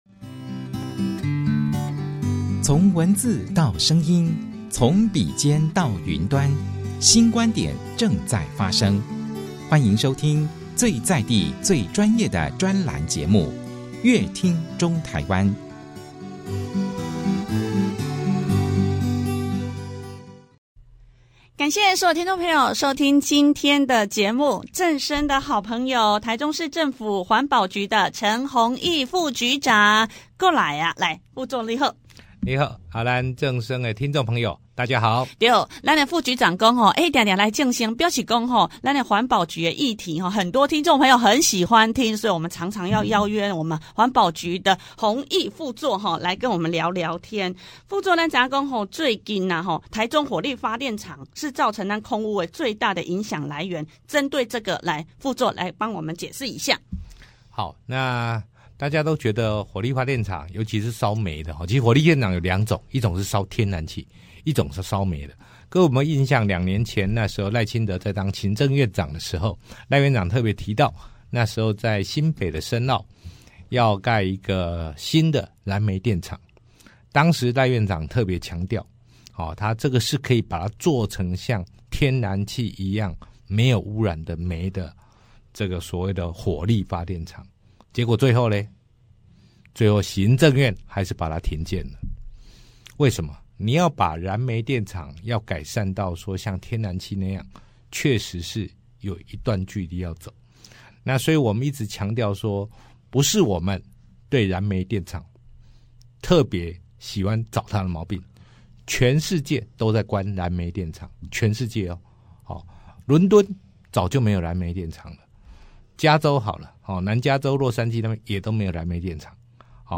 大台中的環保議題 專訪：台中市政府環保局陳宏益副局長 近年來，空氣汙染逐漸成為中部人的夢魘，也影響了中台灣人民